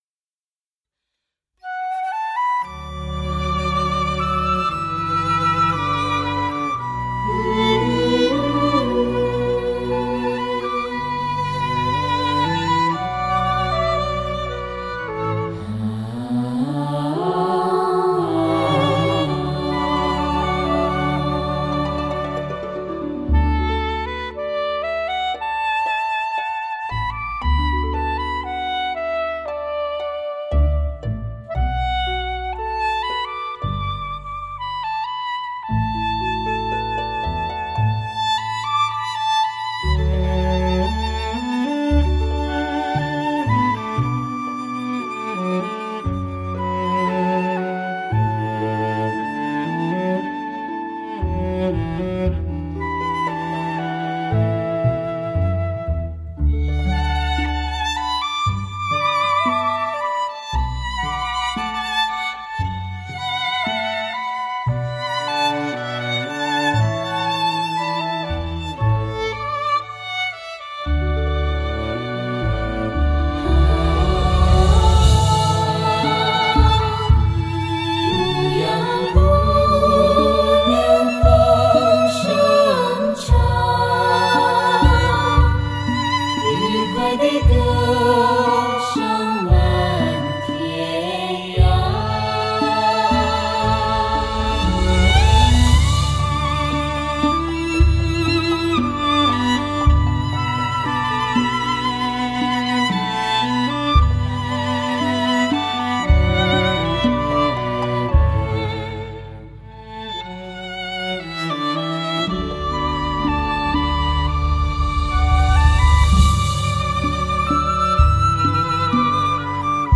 长笛
萨克斯
大提
小提
贝司
低音手鼓、手鼓、吊钹
合唱